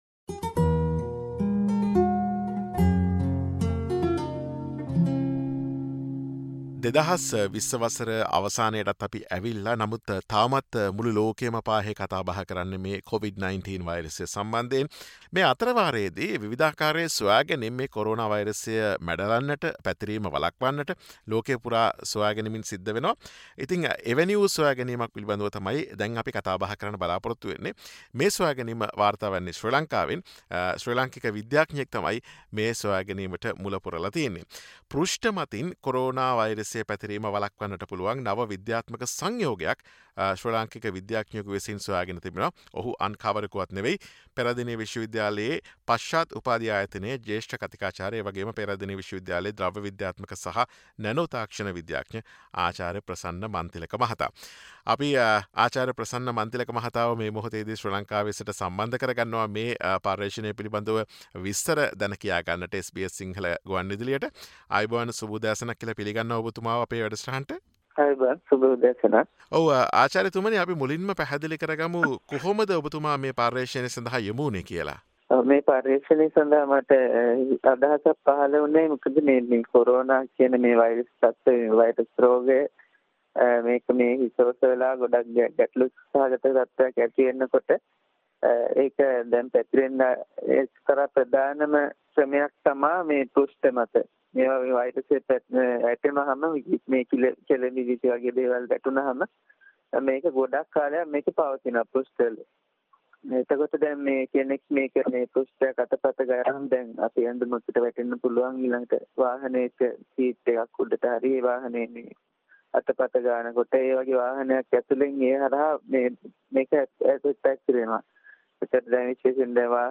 sinhala_interview_-_new_scientific_compound.mp3